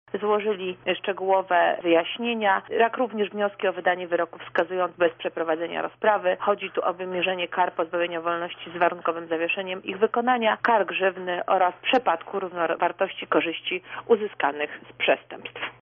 Oskarżeni przyznali się do zarzucanych im czynów. – dodaje rzeczniczka.